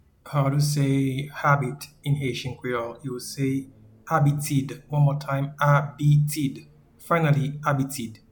Pronunciation:
Habit-in-Haitian-Creole-Abitid.mp3